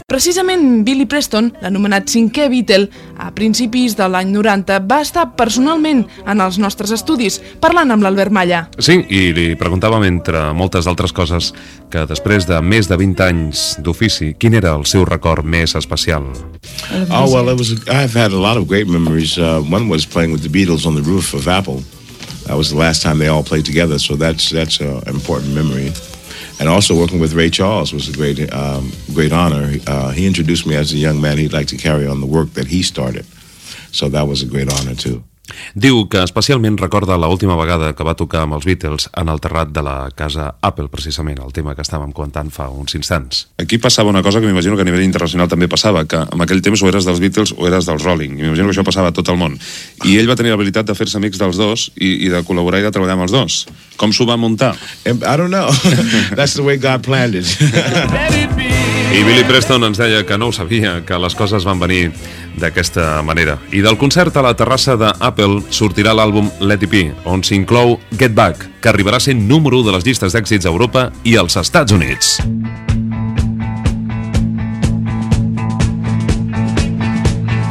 Record d'una entrevista feta al músic Billy Preston.
Musical